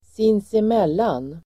Ladda ner uttalet
sinsemellan adverb, between (among) ourselves (yourselves, themselves) Uttal: [sinsem'el:an] Definition: emellan sig; inbördes; ömsesidigt Exempel: de bråkade aldrig sinsemellan (they never fought among themselves)